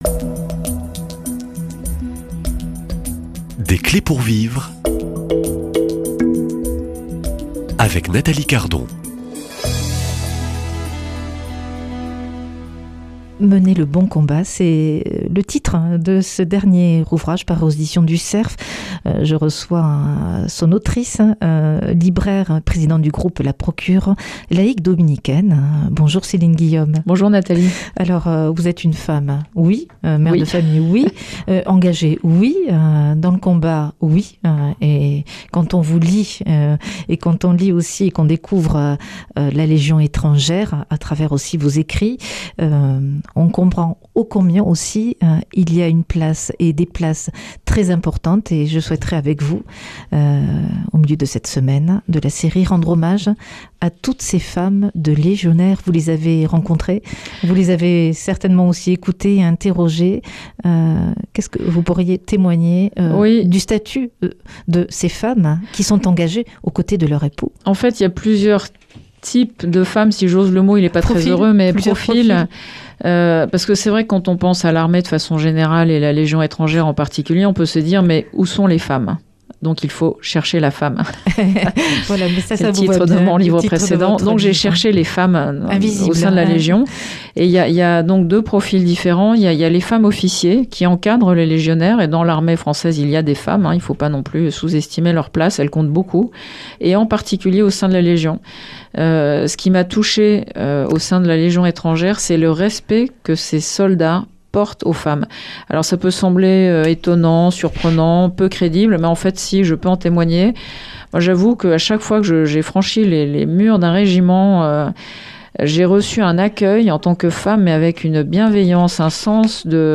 Invitée